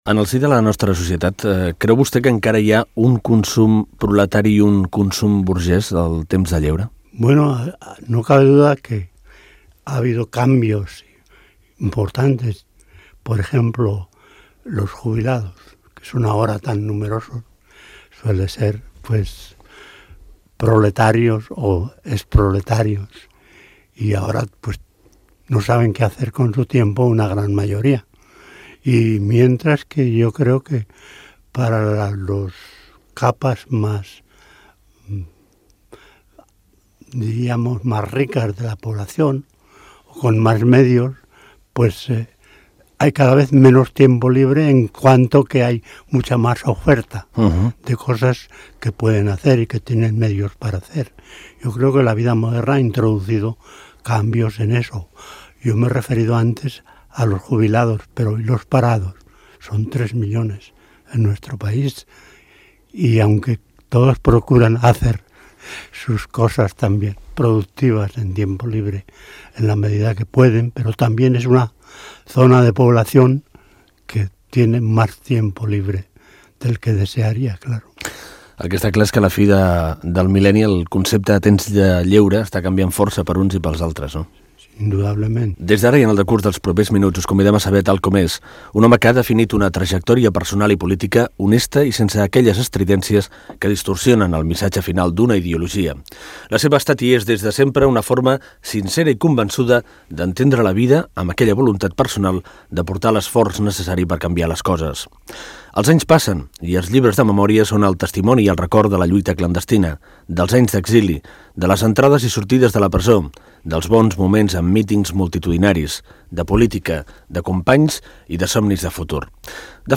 Fragment d'una entrevista al polític Gregorio López Raimundo.